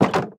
Minecraft Version Minecraft Version snapshot Latest Release | Latest Snapshot snapshot / assets / minecraft / sounds / block / fence_gate / open2.ogg Compare With Compare With Latest Release | Latest Snapshot